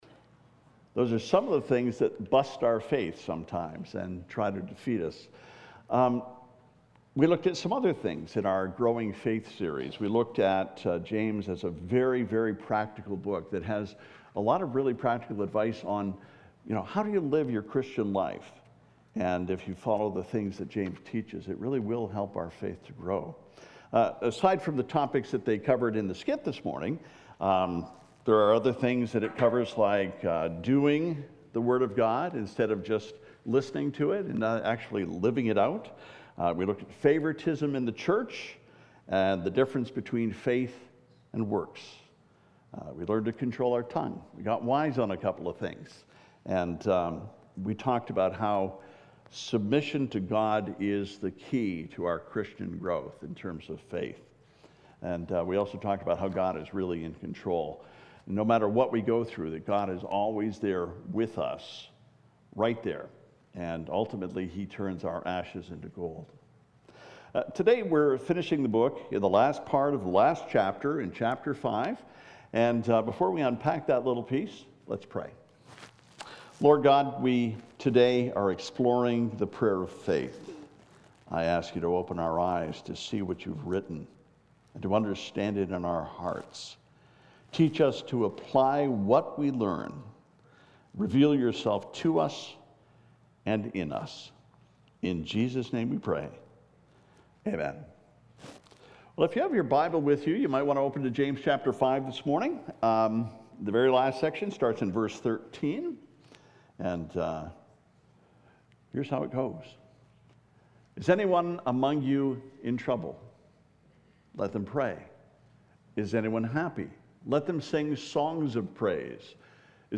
Growing Faith Part 9: “The Prayer of Faith” James 5:13-20 « FABIC Sermons